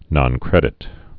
(nŏn-krĕdĭt)